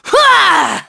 Aselica-Vox_Attack3.wav